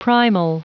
Prononciation du mot primal en anglais (fichier audio)
Prononciation du mot : primal